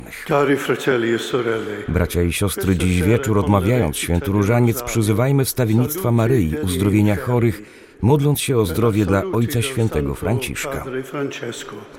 W trakcie wtorkowej modlitwy słowa do wiernych skierował angielski kardynał Atrhur Roche, który tym razem przewodniczył modlitwie.